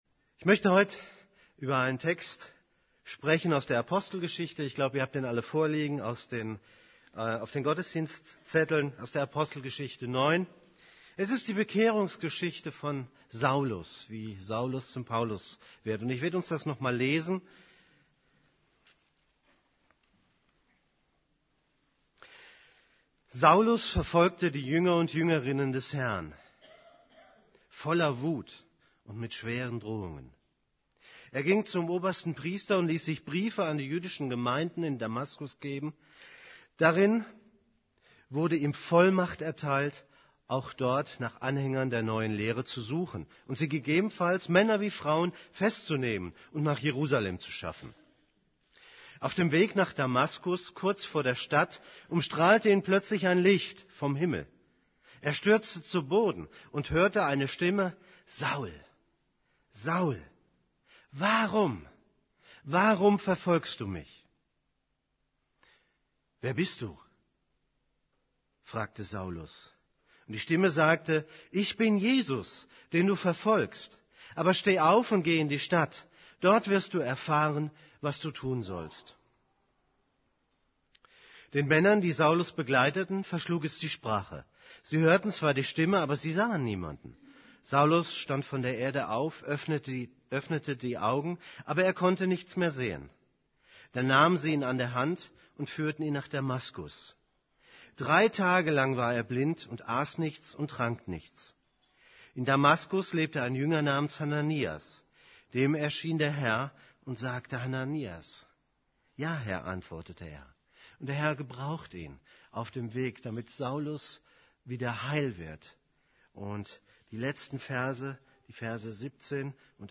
Vorstellungspredigt